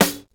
Boom-Bap Snare 79.wav